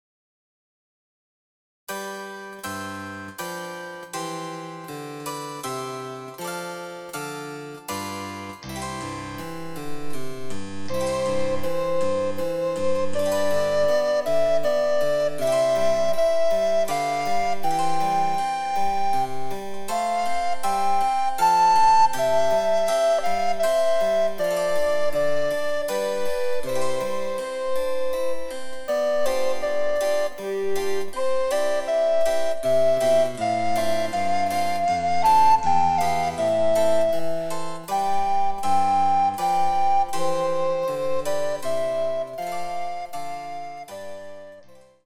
チェンバロ伴奏で楽しむ日本のオールディーズ！
※伴奏はモダンピッチのみ。